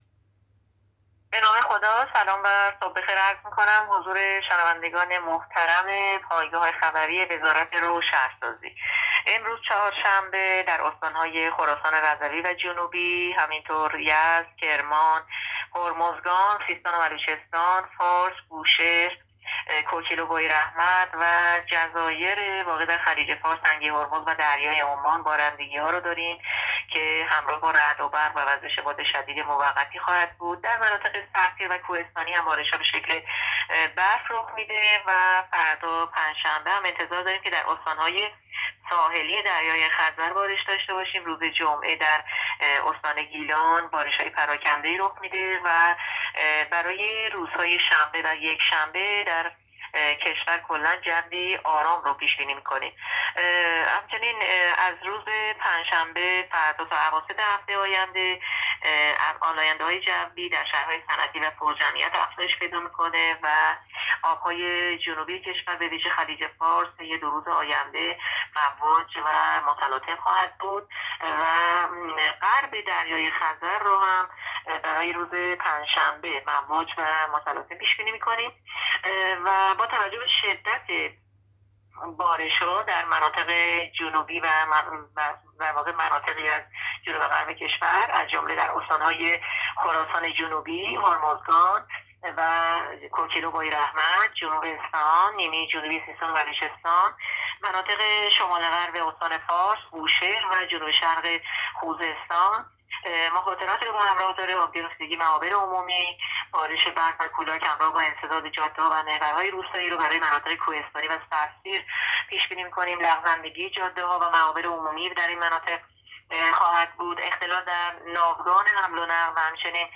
گزارش رادیو اینترنتی پایگاه‌خبری از آخرین وضعیت آب‌وهوای هفتم دی؛